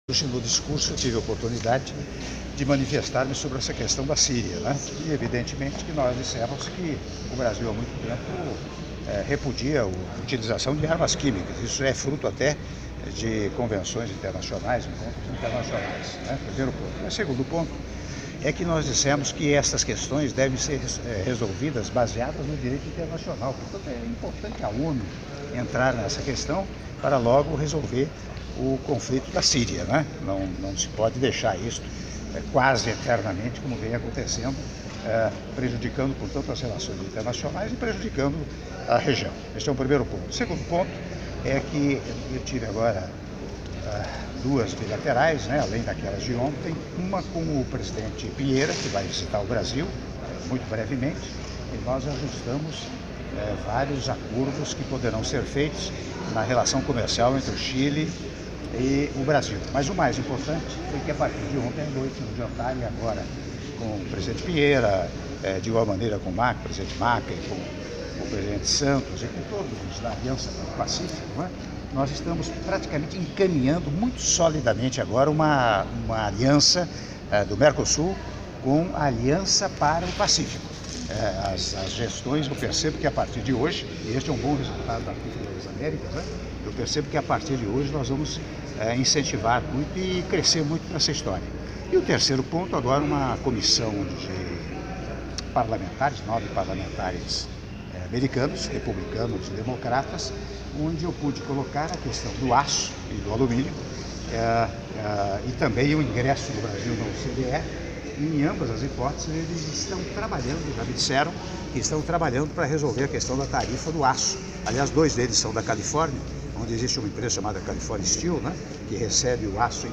Áudio da entrevista coletiva concedida pelo Presidente da República, Michel Temer, após Reunião Bilateral com o Senhor Sebastián Piñera, Presidente da República do Chile - Lima/Peru - (04min00s)